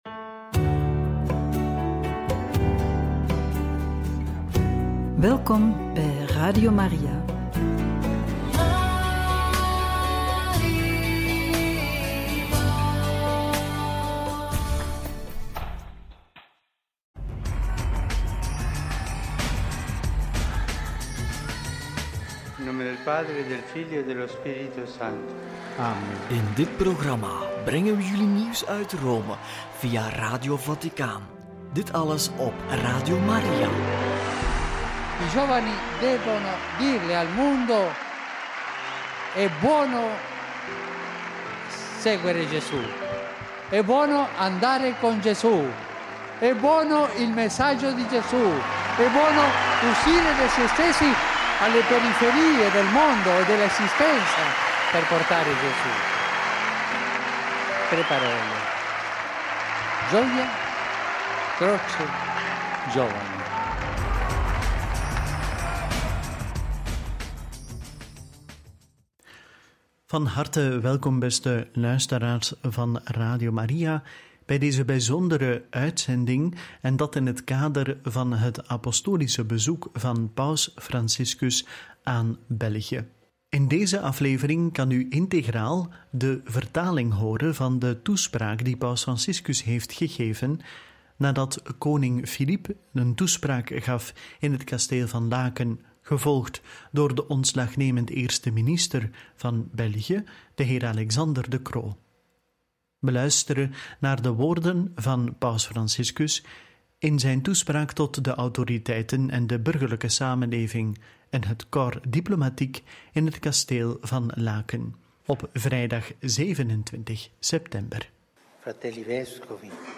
27/9 Paus Franciscus geeft toespraak aan de Belgische autoriteiten in het Kasteel van Laken – Radio Maria
27-9-paus-franciscus-geeft-toespraak-aan-de-belgische-autoriteiten-in-het-kasteel-van-laken.mp3